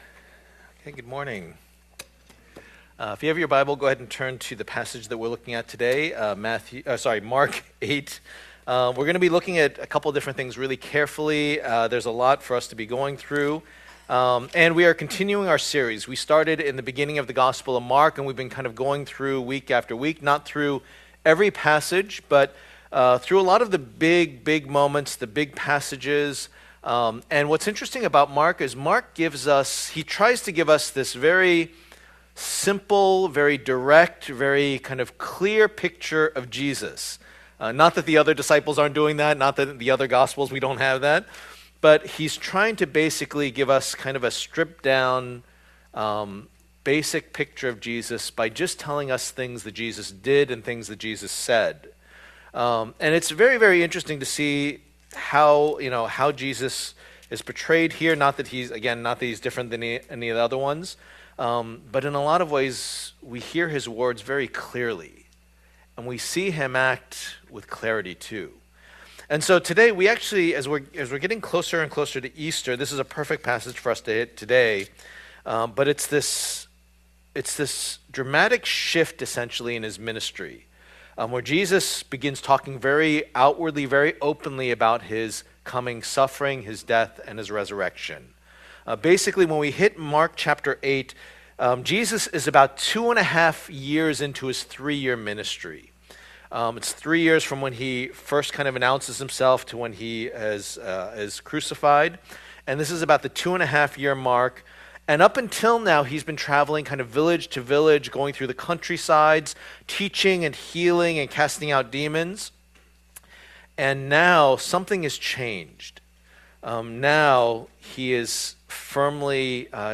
The Gospel of Mark Passage: Mark 8:27-9:1 Service Type: Lord's Day « Food and Following The Messiah’s Mission